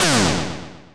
snd_rudebuster_swing.wav